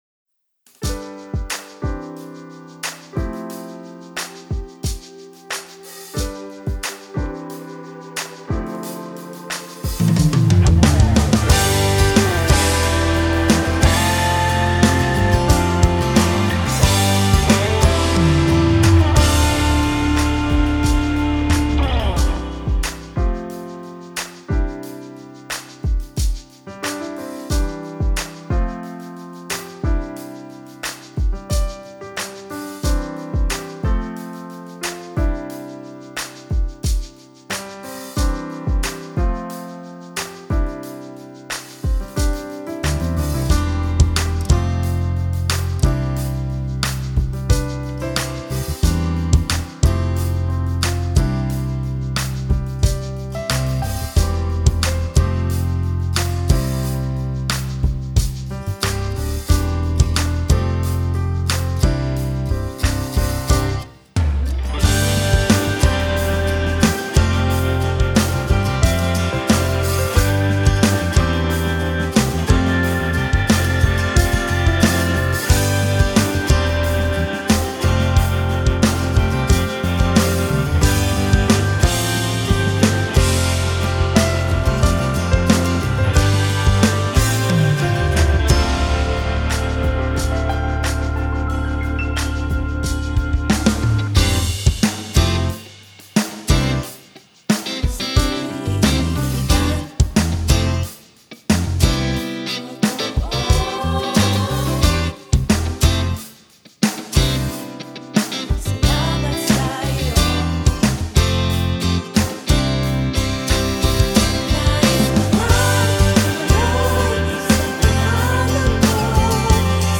Minus One